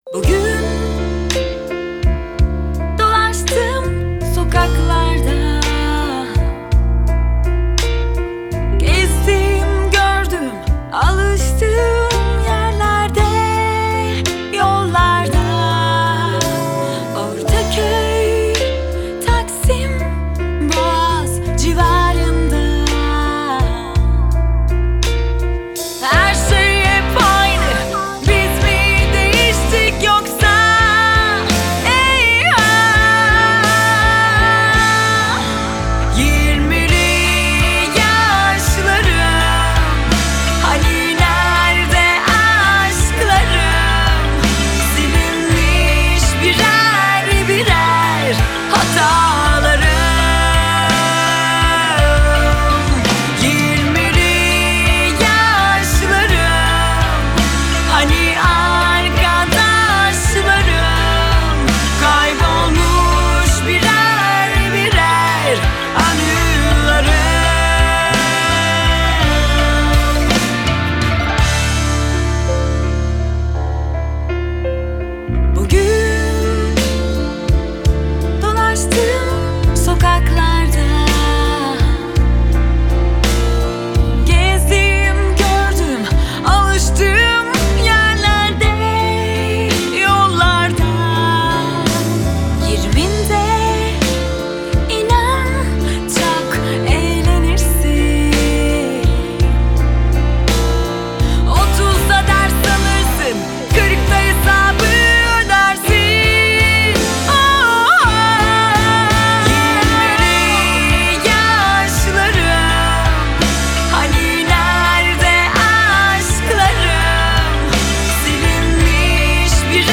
Genre: Pop Rock